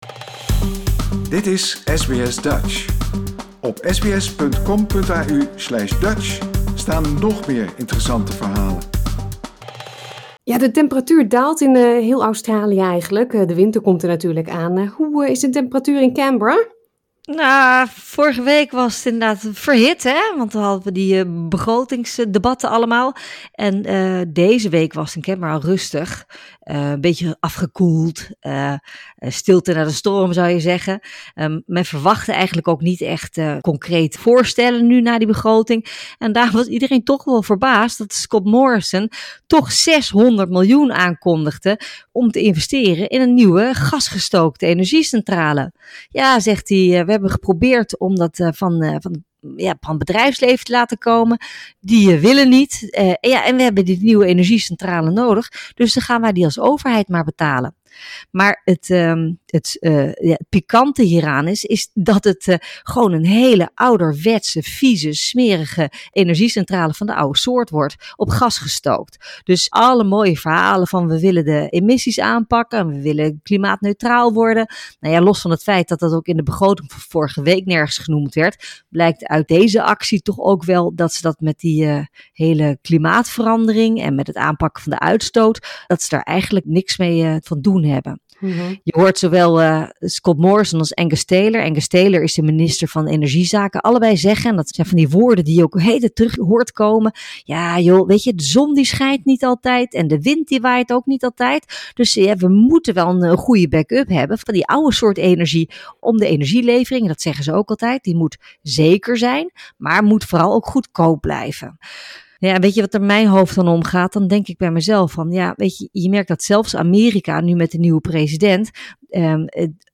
Deze week kondigde premier Scott Morisson aan dat de regering doorgaat met de bouw van een ouderwetse gasgestookte energiecentrale in de Hunter Valley (NSW). Dat, maar ook Penny Wong's kritiek op de manier waarop de premier China aanpakt en het gevaar voor een nieuwe 'Roaring Twenties', bespreken we met politiek commentator